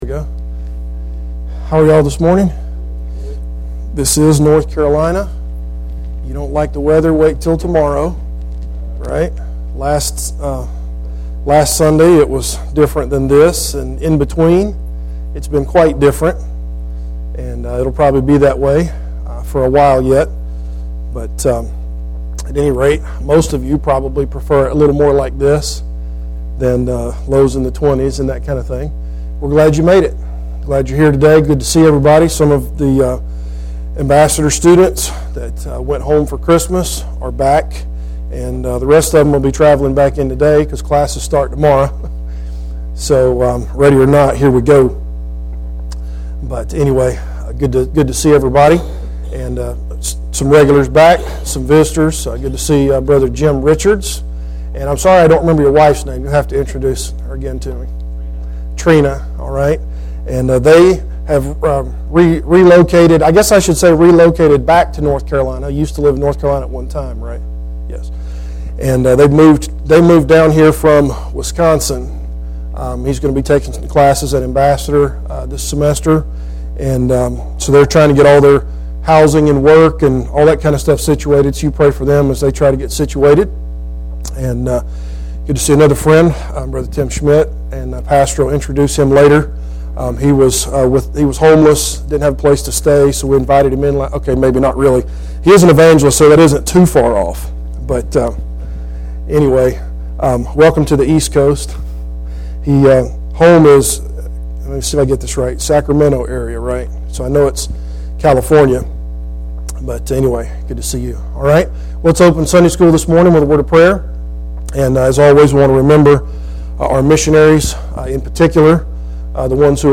Psalm 19:1-3 Service Type: Adult Sunday School Class Bible Text